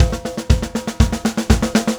Power Pop Punk Drums 03 Fill.wav